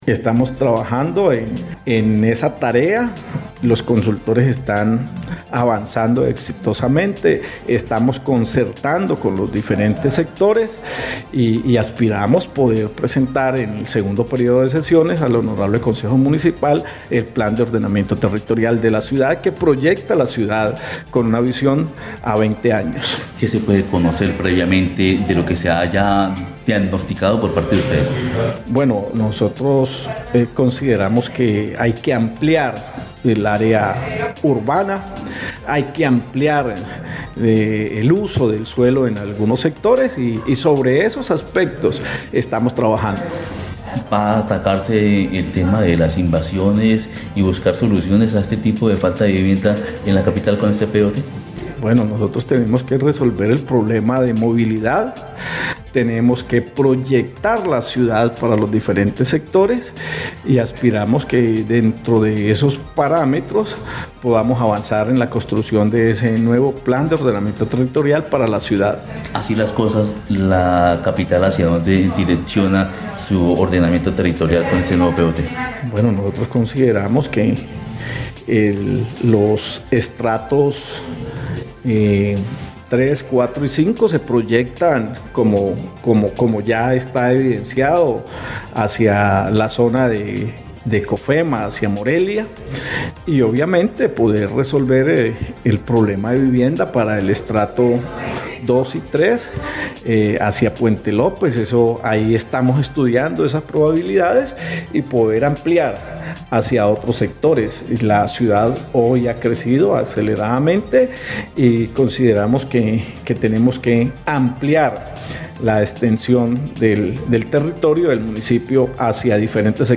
Luis Antonio Ruiz Cicery, alcalde del municipio, explicó que para el caso de los estratos 3, 4 y 5, estos se proyectan hacía sectores como COFEMA y zona limítrofe con la localidad de Morelia, cosa que ya está sucediendo.
02_ALCALDE_LUIS_ANTONIO_RUIZ_POT.mp3